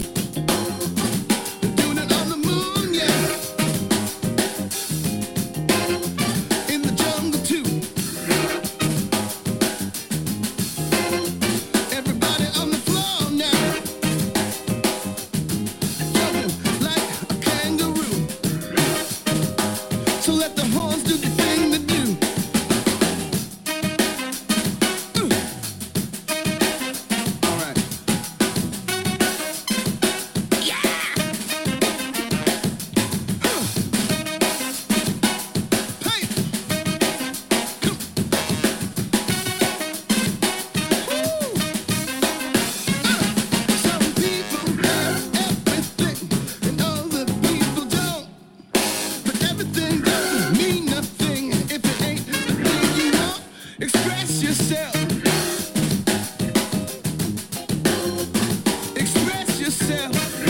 jazz-funk
drums